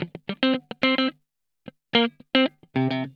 PICKIN 3.wav